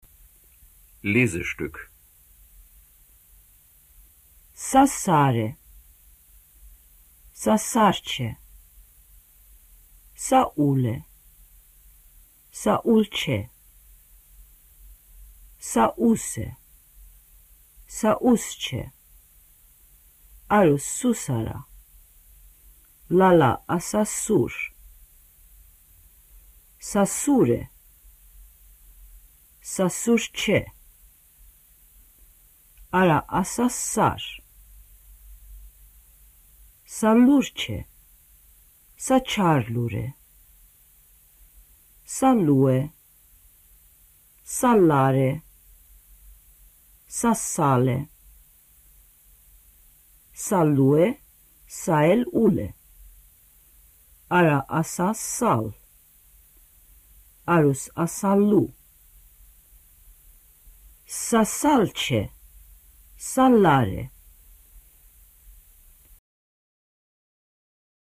Aussprache der Buchstaben, Vokabeln und Lesestücke der Lektionen 1 bis 7 aus dem "Lehrbuch der armenischen Sprache".